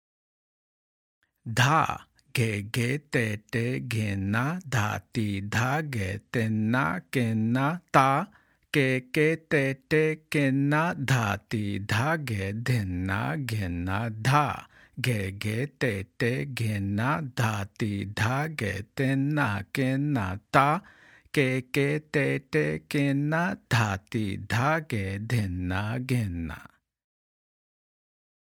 Spoken – Slower Speed